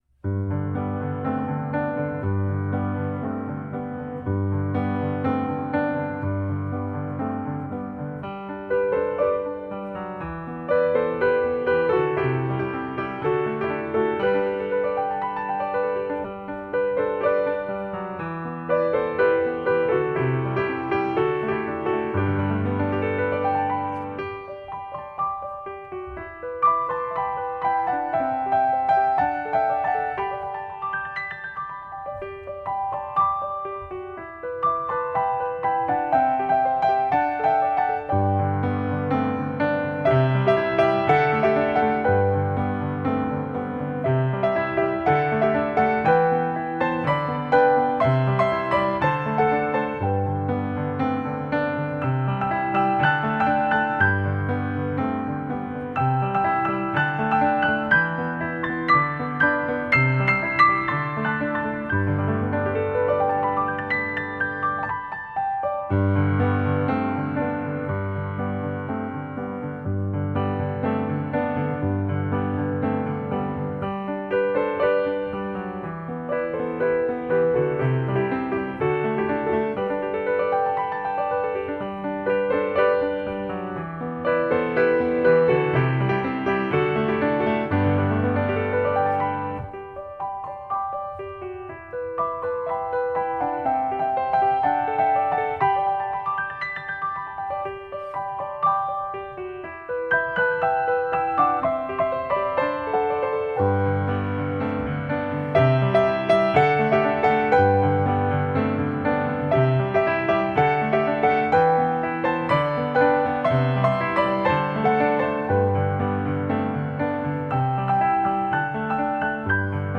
音樂類型：纯音乐
整張專輯風格抒情